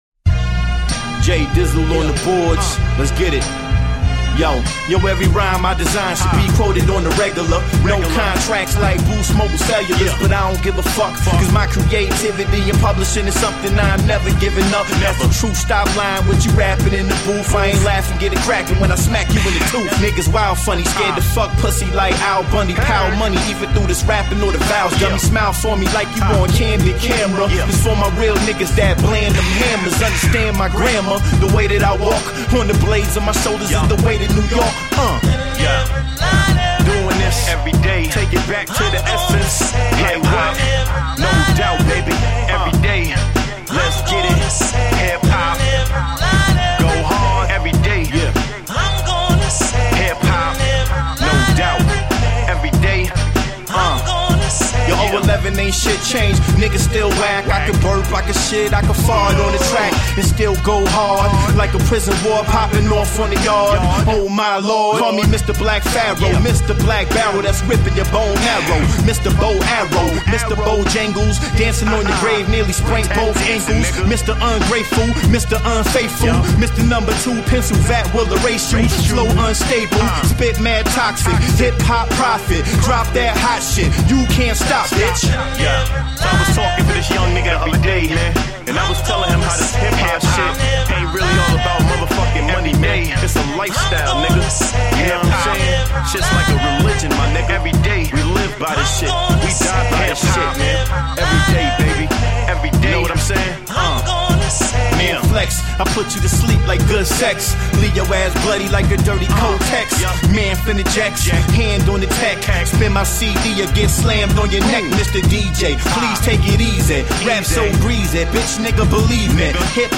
Award winning independent hip hop group.
Tagged as: Hip Hop, Funk